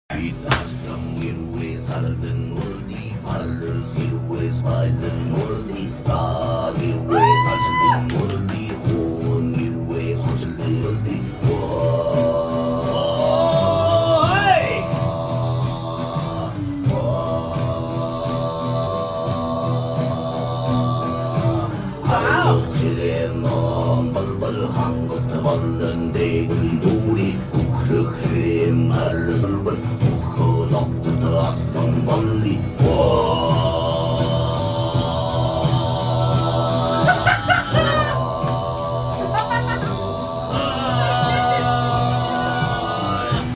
蒙古呼麥 • 狼圖騰！/ Mongolian Khoomei Sound Effects Free Download